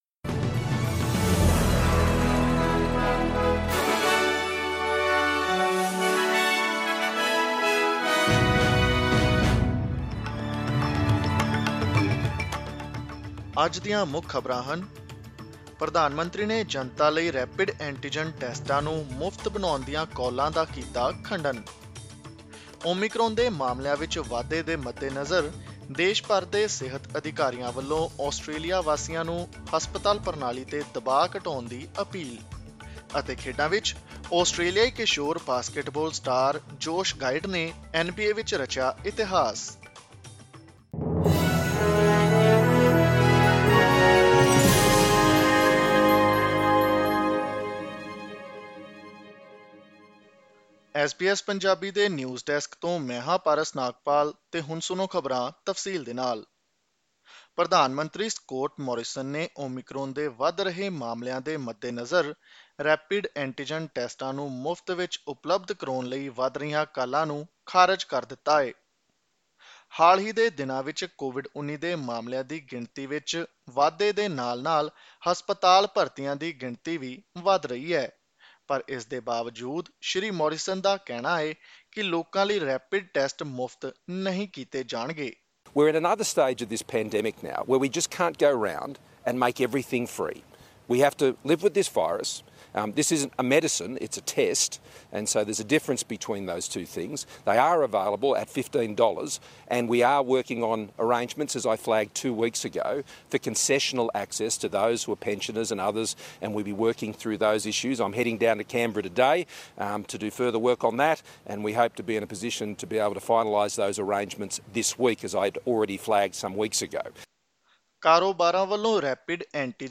Listen to the latest news headlines in Australia from SBS Punjabi radio.